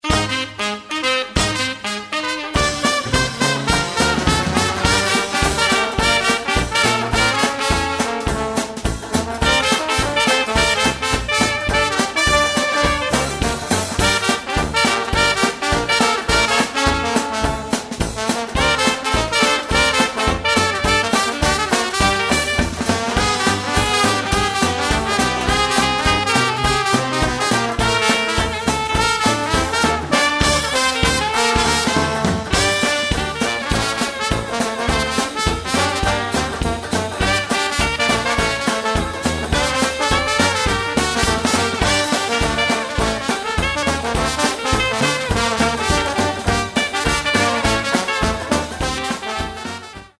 Саундтреки [70]